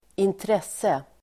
Uttal: [²intr'es:e]